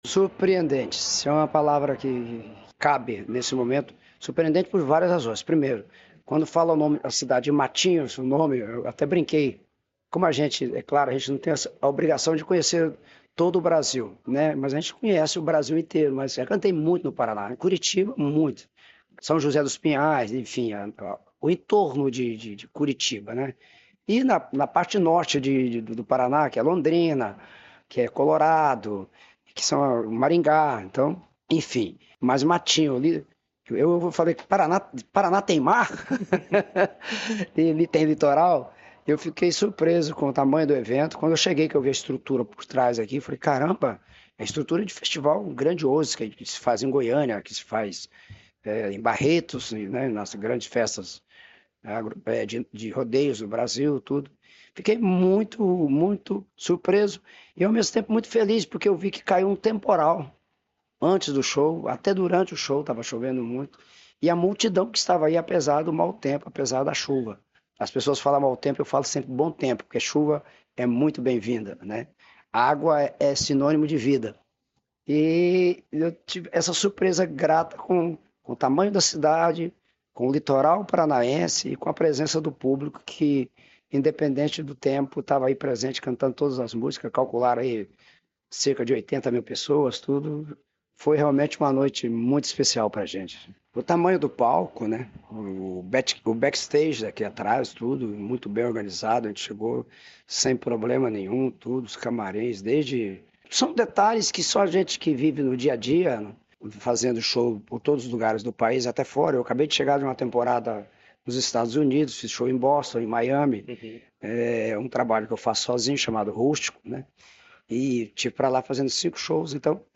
Sonora do cantor Zezé Di Camargo sobre o show no Verão Maior Paraná em Matinhos